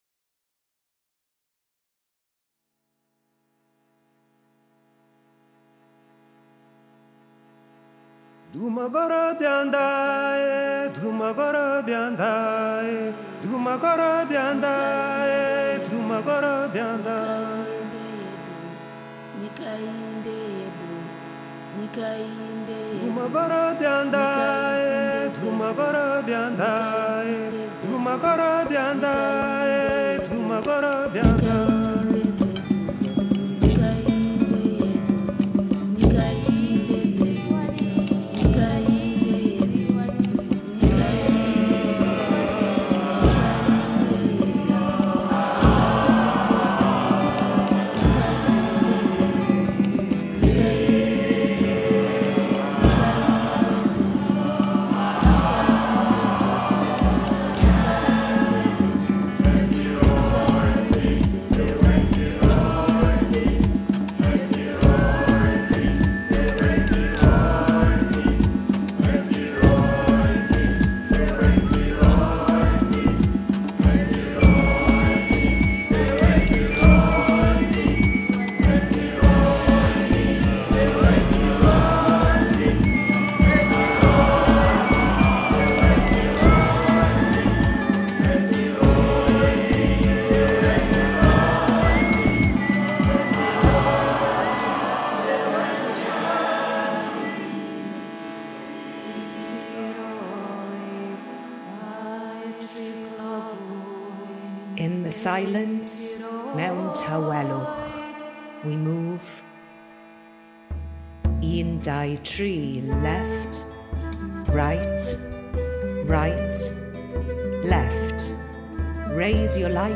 This is a community arts/ wellbeing and participatory project, with the aim to welcome people who have been displaced from their home countries.
We have fun singing and creating our own joint songs as well as listening to each other.